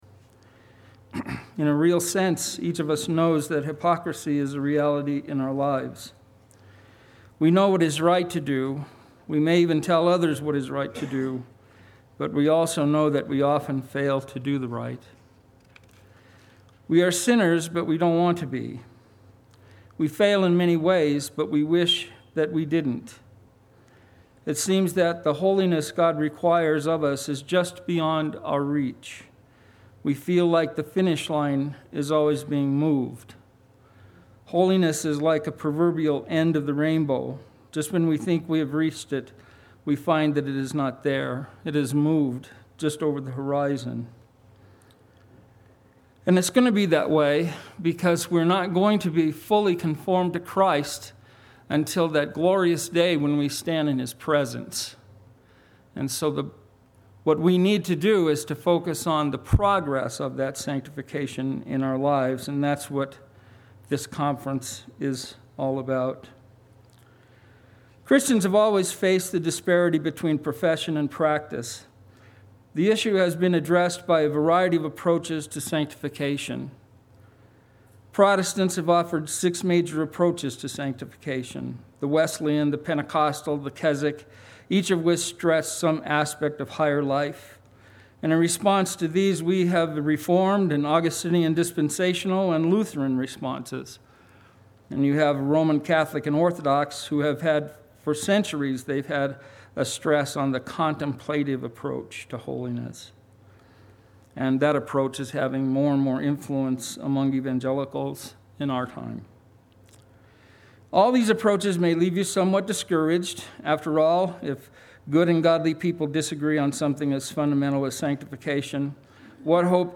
Ongoing Reformation Conference - 04/17/15